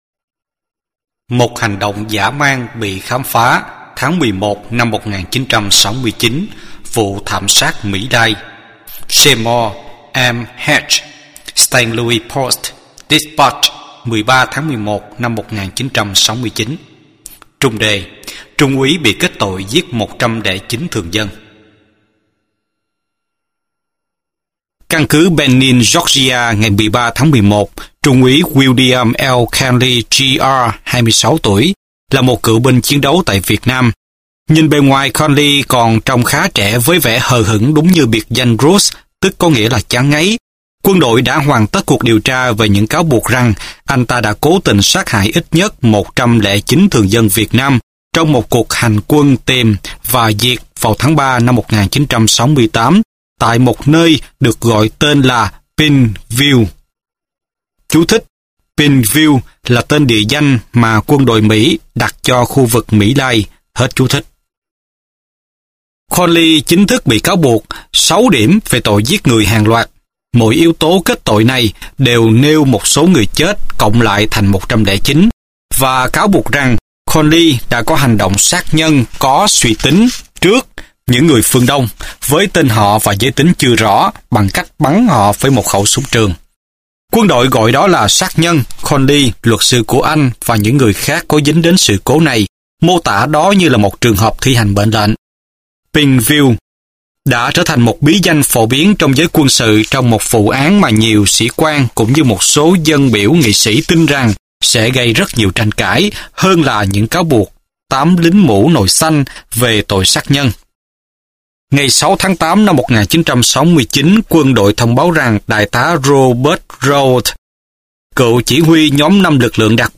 Sách nói